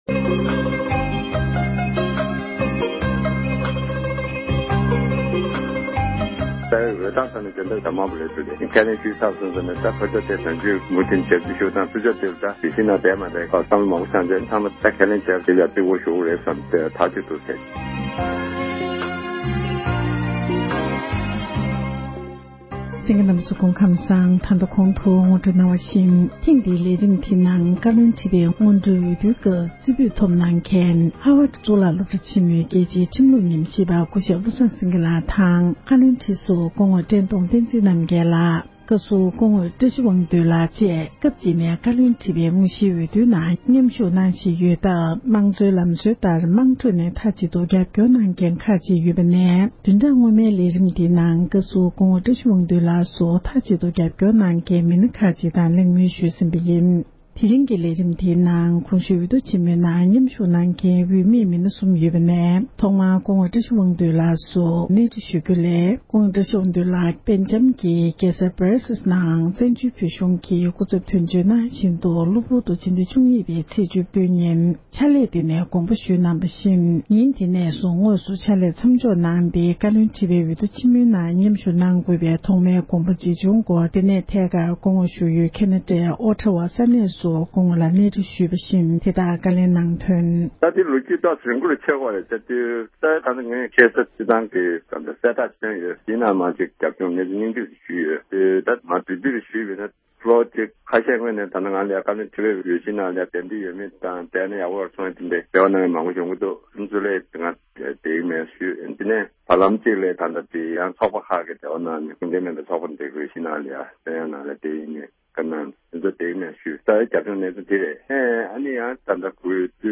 སྐུ་ངོ་དང་ལྷན་དུ་བཀའ་མོལ་ཞུས་པར་གསན་རོགས༎